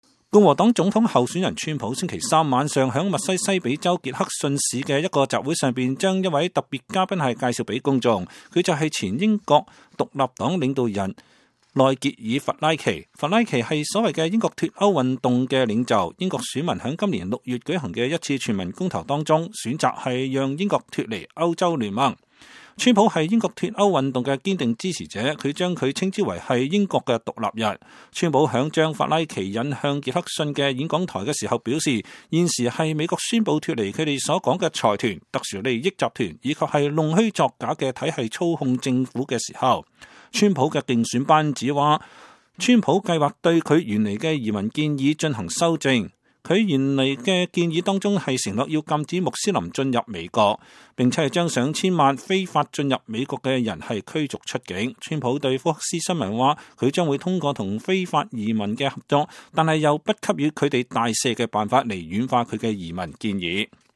2016-08-25 美國之音視頻新聞: 英國脫歐運動領袖在川普競選集會上發表演說